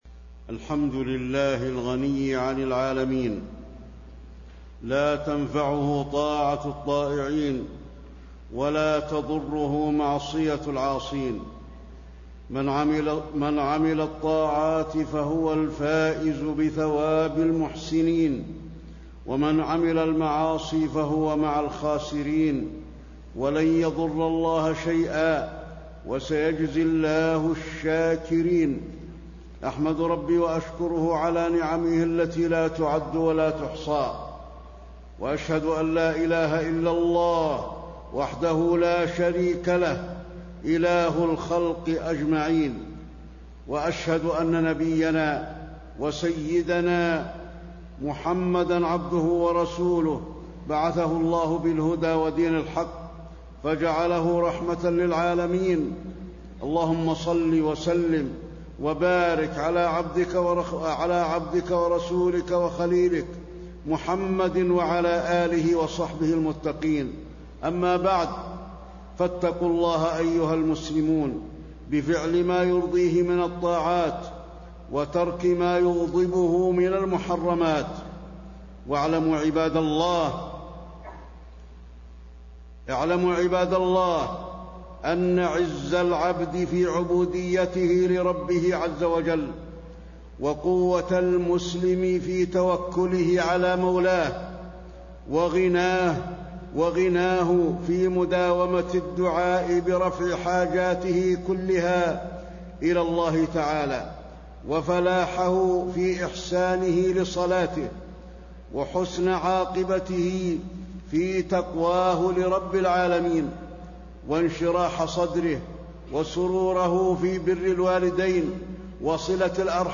تاريخ النشر ١٠ جمادى الأولى ١٤٣٤ هـ المكان: المسجد النبوي الشيخ: فضيلة الشيخ د. علي بن عبدالرحمن الحذيفي فضيلة الشيخ د. علي بن عبدالرحمن الحذيفي كثرة أبواب الخير